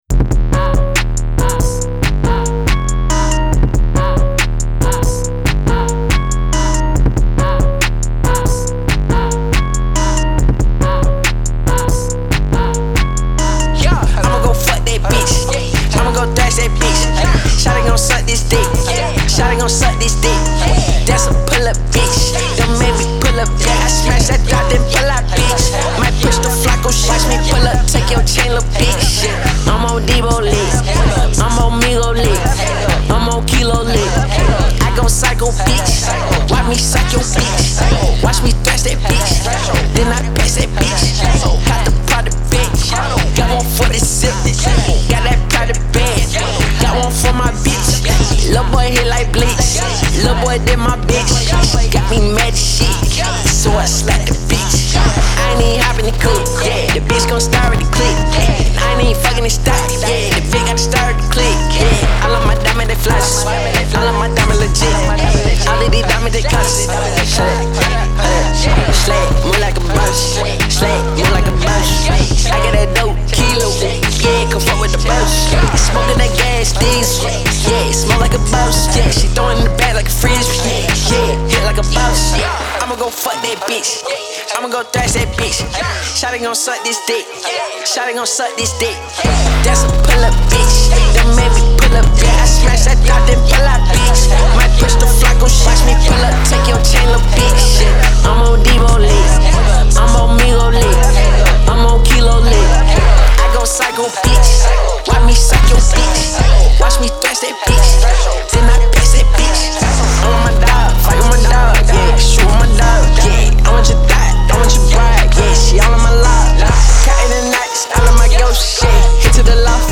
Hip-Hop Rage